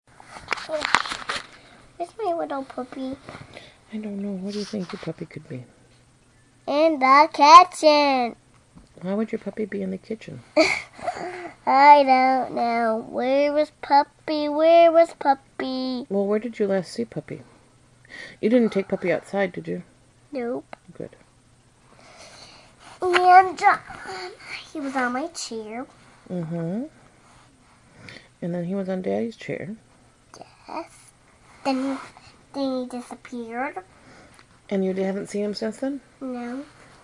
Conversation snippet with extracted words in context